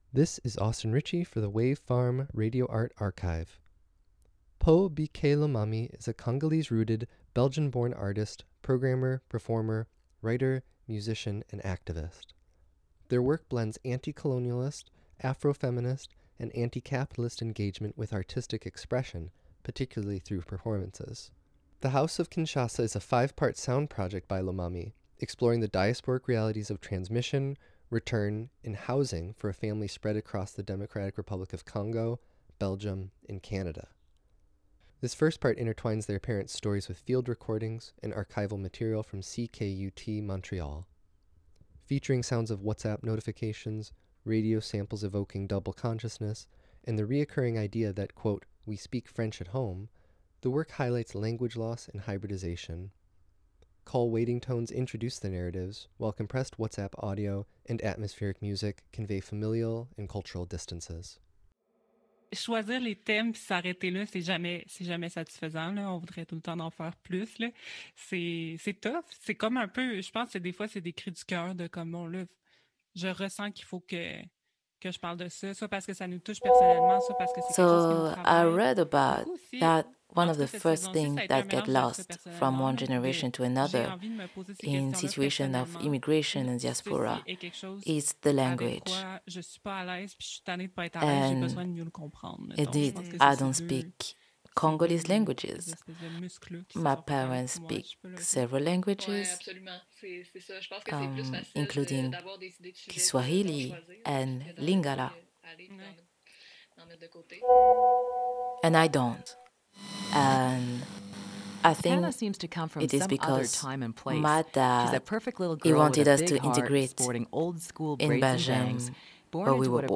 Wave Farm + WGXC Acra Studio